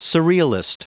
Prononciation du mot surrealist en anglais (fichier audio)
Prononciation du mot : surrealist